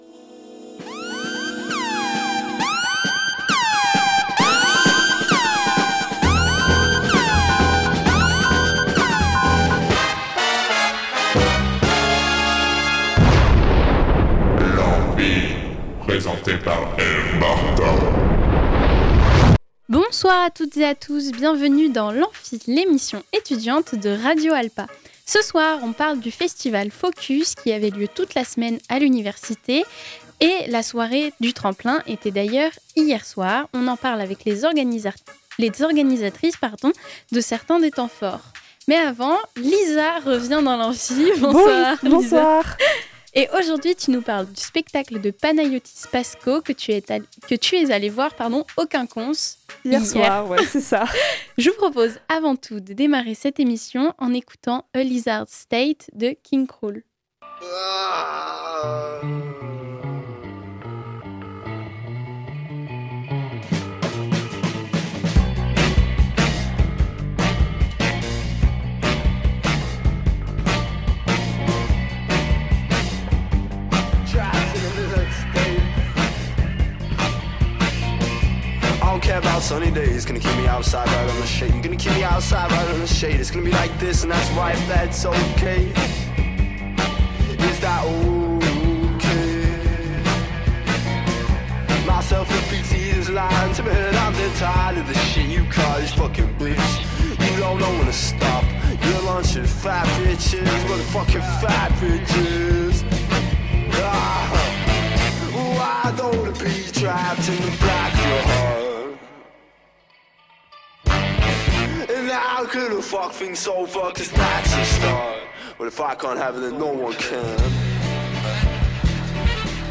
La Carotte est une émission consacrée au rap indépendant, principalement anglophone, mais qui tend de plus en plus vers des horizons variés. On y retrouve des styles relativement différents, allant du rap alternatif, au boom bap, en passant par le noise rap, le lofi hip-hop et l’abstract hip-hop.